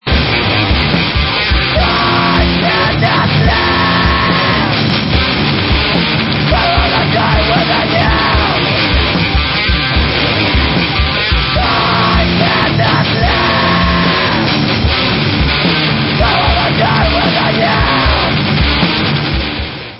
Belgian metal/rock/core